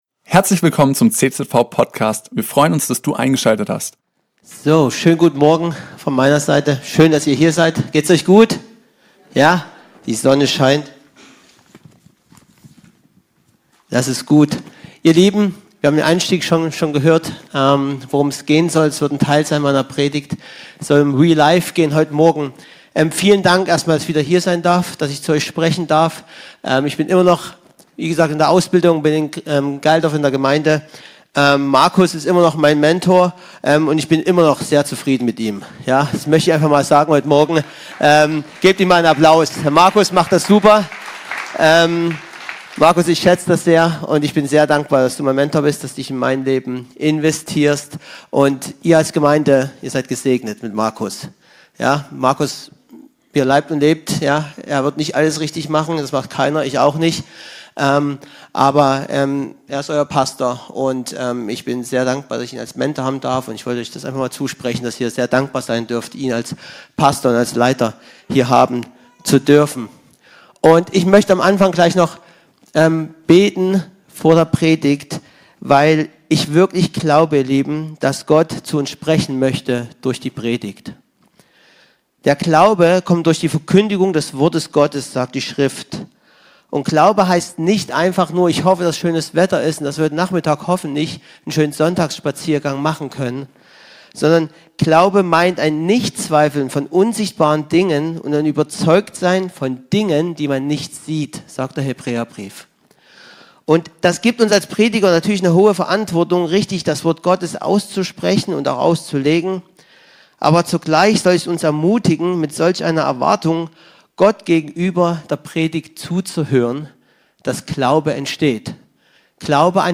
ging in seiner Predigt darauf ein, was das wahre Leben ist und wofür wir eigentlich leben.